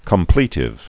(kəm-plētĭv)